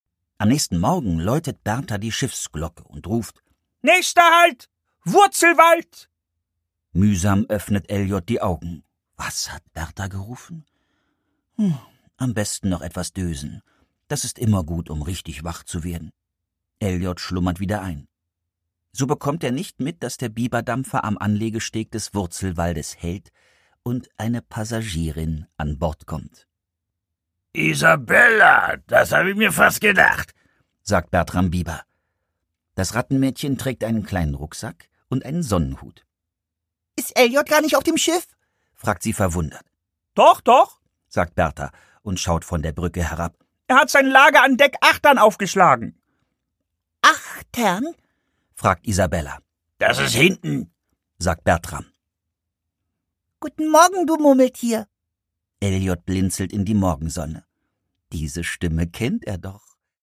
Produkttyp: Hörbuch-Download
Gelesen von: Stefan Kaminski
Eliot und Isabella sind seine erklärten Lieblingskinderbuchhelden, und er verleiht ihnen, ihren Freunden und Feinden grandiose Stimmen.